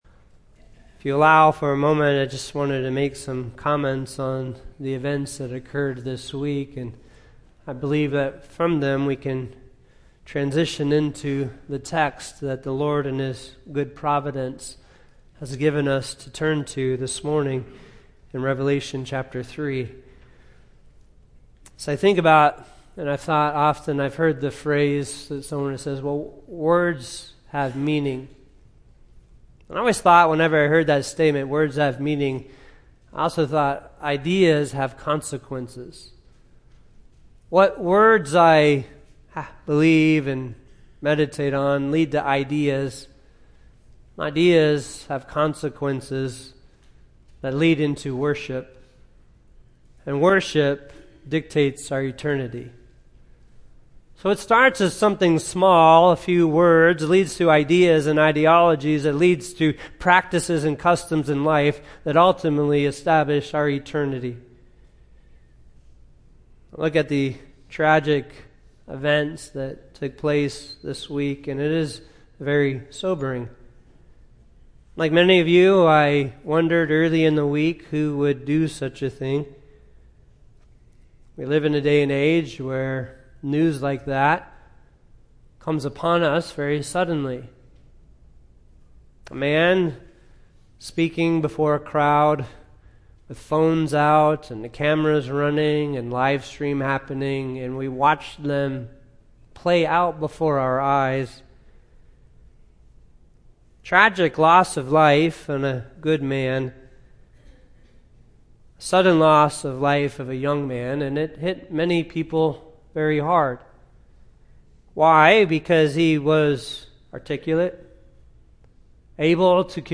Sermon Audio
Sermons from Saving Grace Bible Church: Venice, FL
sermon-9-14-25.mp3